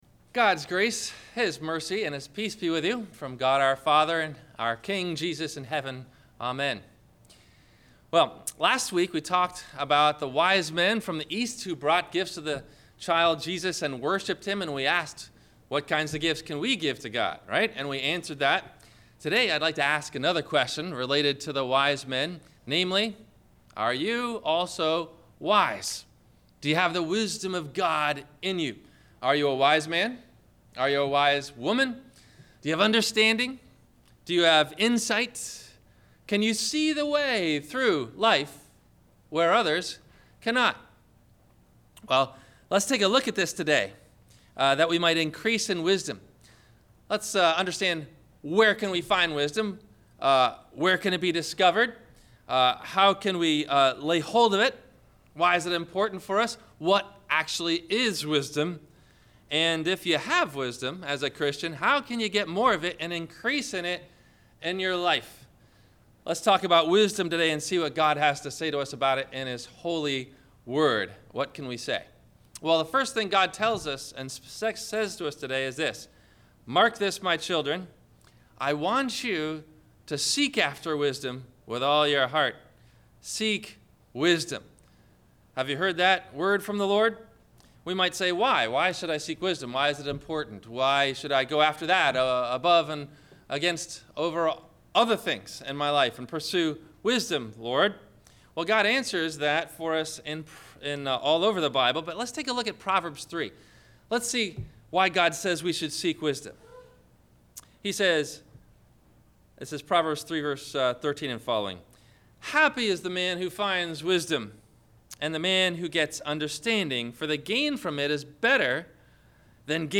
Is It Wise To Follow Your Heart? – Sermon – February 15 2015